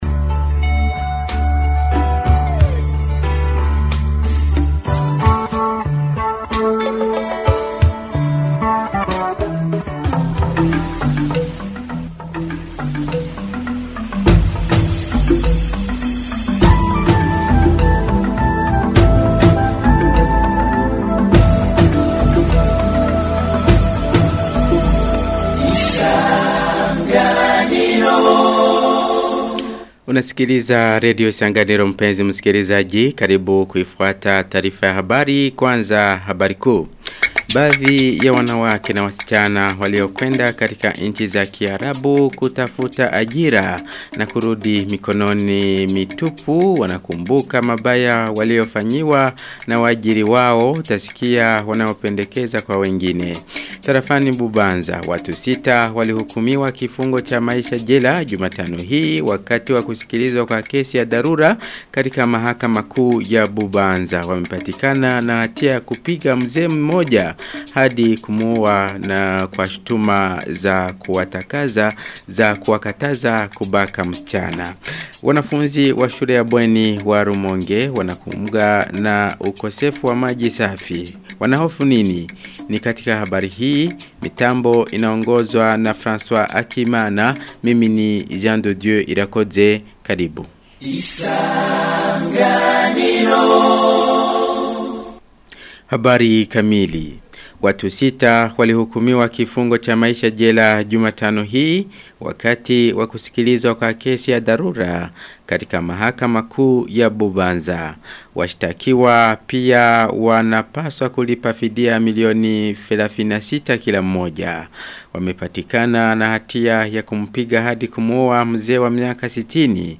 Taarifa ya habari ya tarehe 18 Septemba 2025